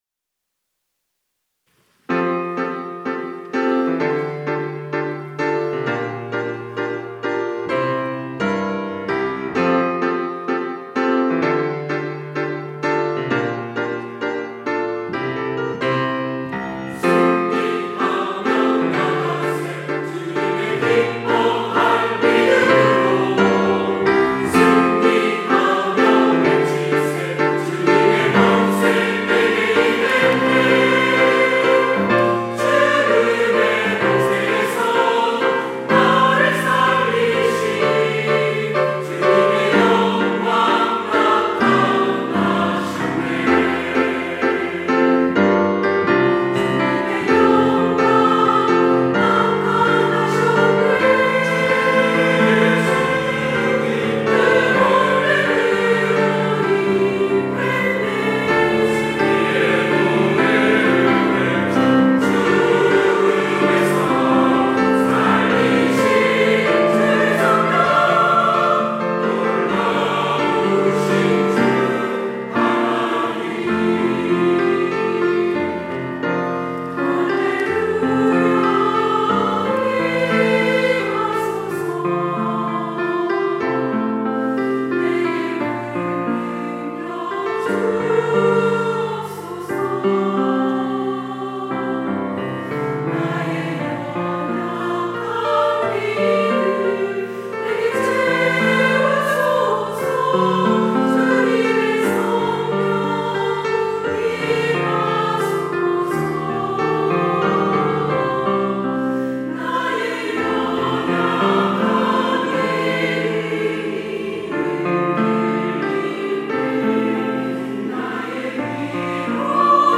호산나(주일3부) - 승리하며 나가세
찬양대